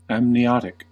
Ääntäminen
Ääntäminen US RP : IPA : /æmniˈɒtɪk/ US : IPA : /æmniˈɑːtɪk/ Haettu sana löytyi näillä lähdekielillä: englanti Käännöksiä ei löytynyt valitulle kohdekielelle.